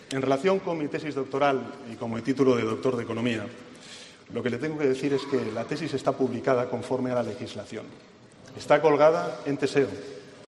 Pedro Sánchez defiende su tesis doctoral en la sesión de control en el Congreso